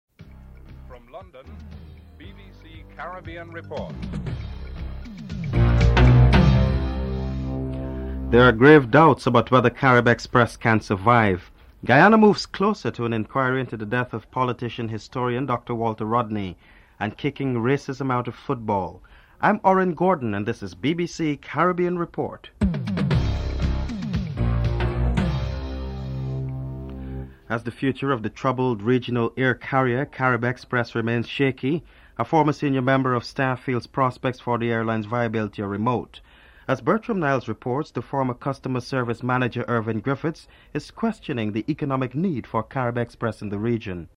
1. Headlines (00:00-00:26)